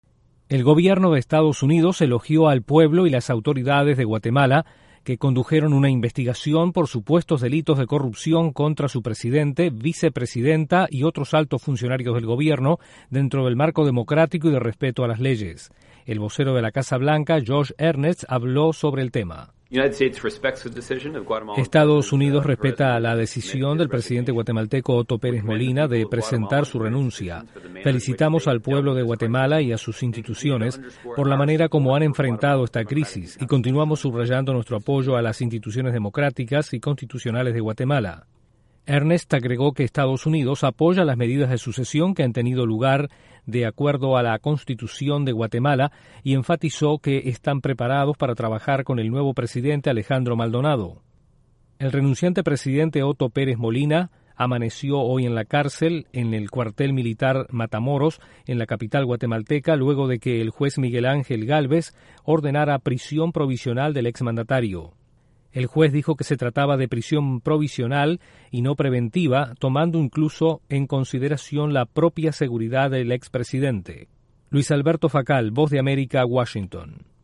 EE.UU. elogió la resolución de la crisis política en Guatemala que llevó a la renuncia del presidente de la nación. Desde la Voz de América en Washington informa